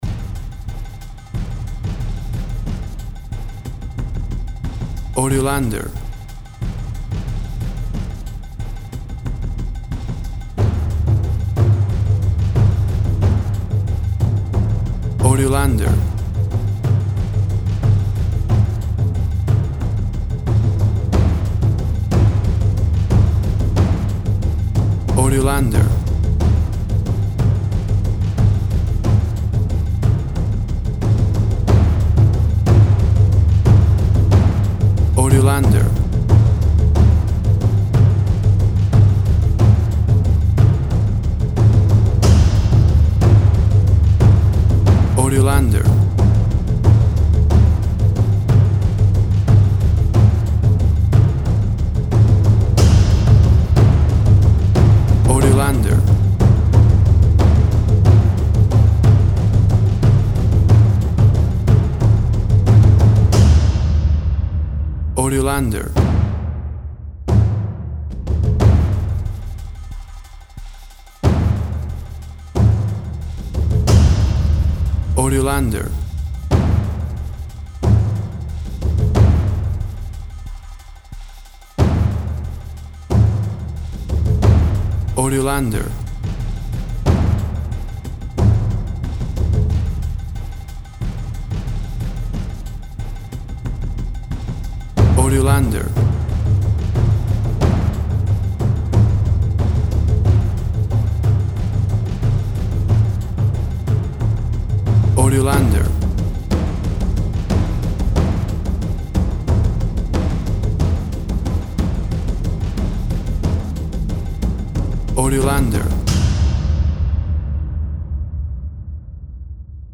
Exotic and world music!
WAV Sample Rate 16-Bit Stereo, 44.1 kHz
Tempo (BPM) 91